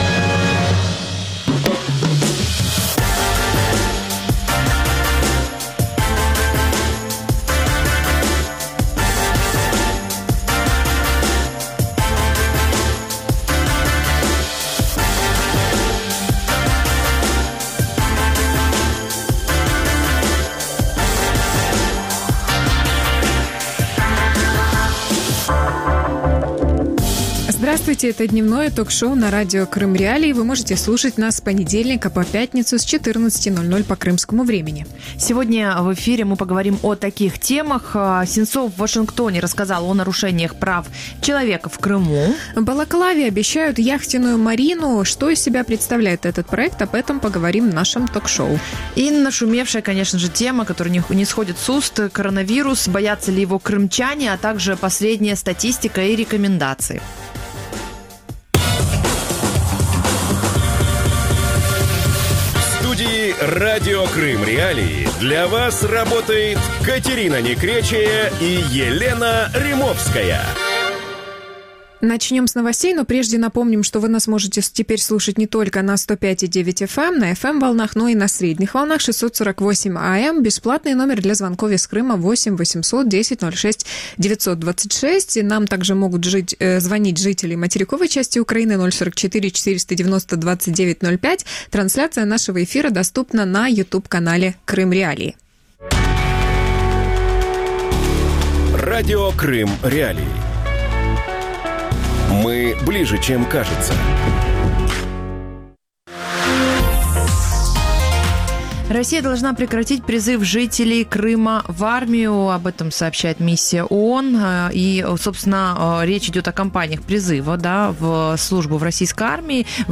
Севастополь. Марина для Путина | Дневное ток-шоу